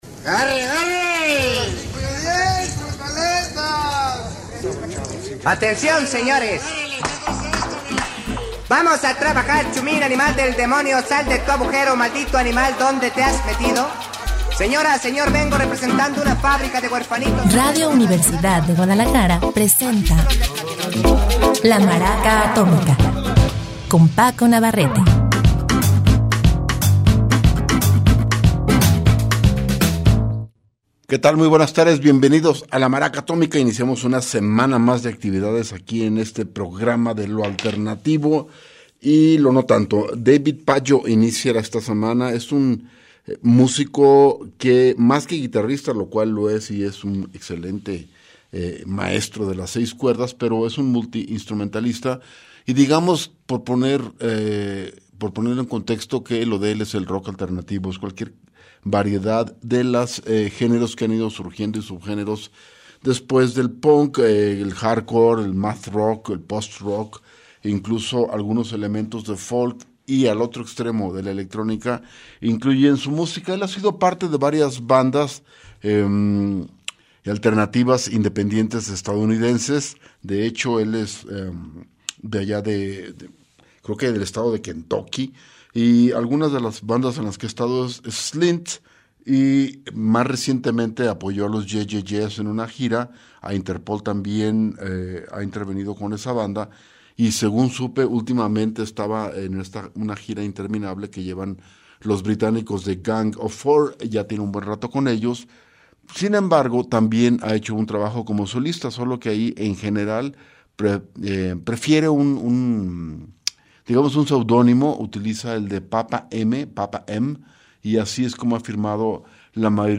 Iniciamos la semana con lo alternativo y no tanto...